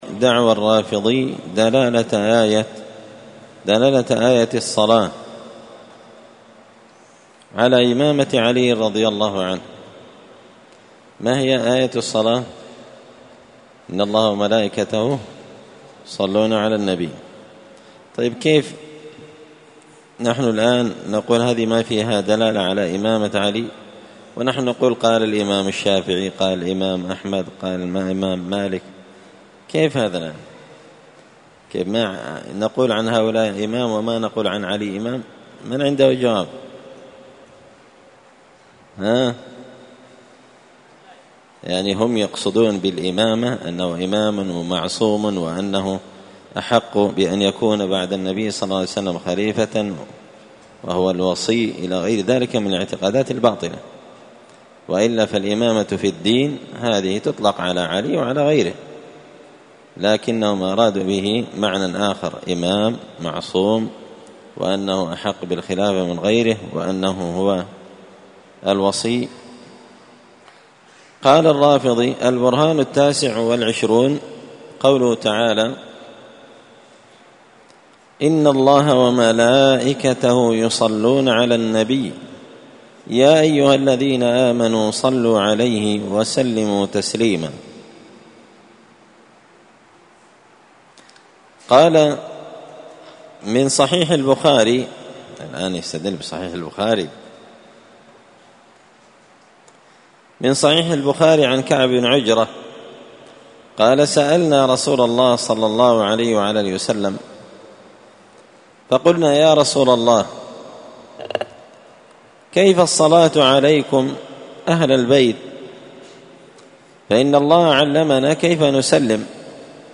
الأربعاء 14 صفر 1445 هــــ | الدروس، دروس الردود، مختصر منهاج السنة النبوية لشيخ الإسلام ابن تيمية | شارك بتعليقك | 7 المشاهدات
مسجد الفرقان قشن_المهرة_اليمن